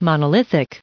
added pronounciation and merriam webster audio
992_monolithic.ogg